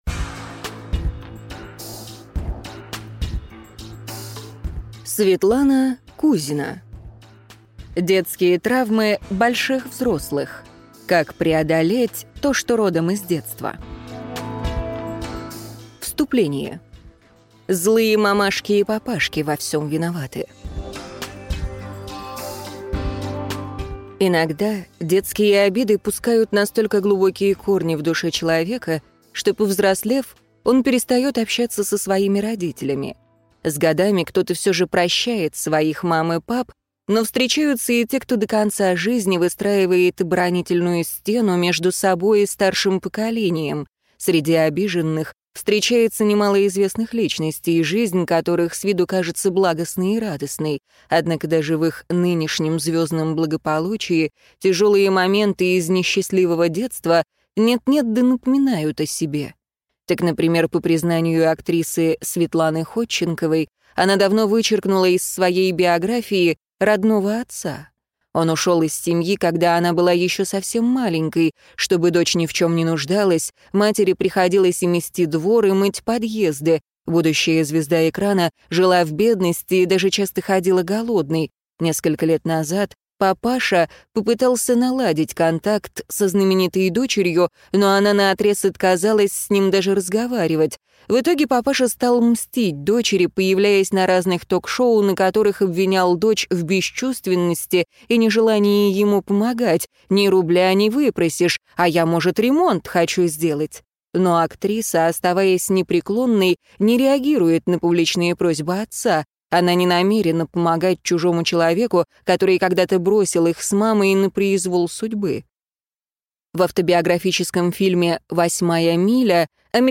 Аудиокнига Детские травмы больших взрослых. Как преодолеть то, что родом из детства | Библиотека аудиокниг